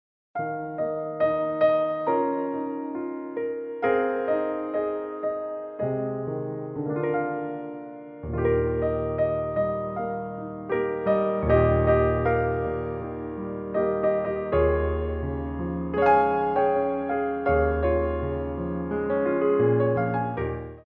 for Ballet Class
Ronds de Jambé à Terre
4/4 (8x8)